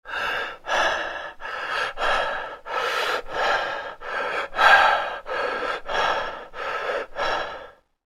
دانلود آهنگ نفس نفس زدن مرد از افکت صوتی انسان و موجودات زنده
دانلود آلبوم صدای نفس نفس زدن مرد از ساعد نیوز با لینک مستقیم و کیفیت بالا
جلوه های صوتی